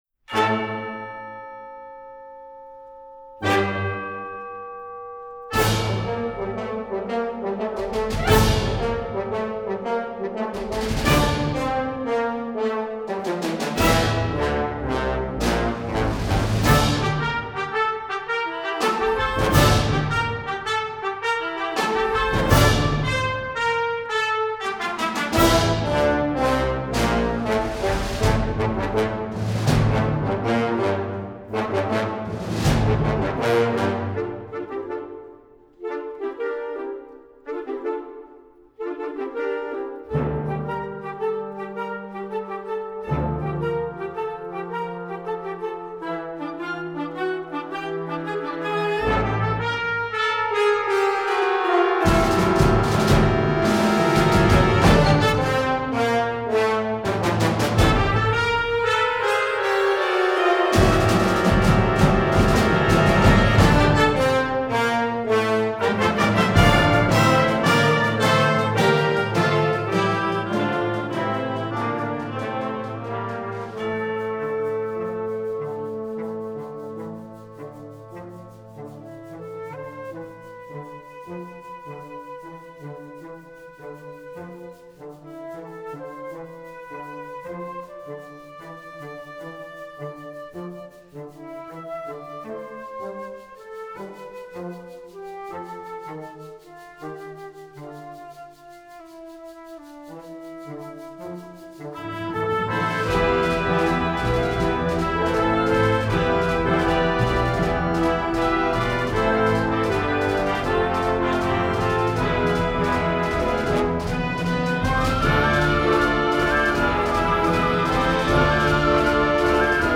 A Mexican Folk Song Symphony for Concert Band